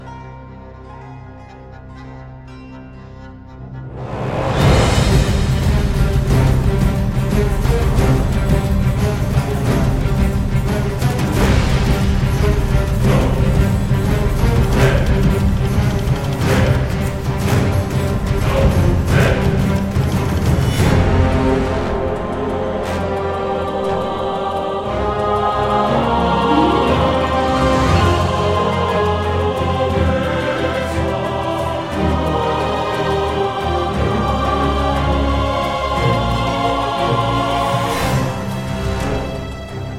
мощные
эпичные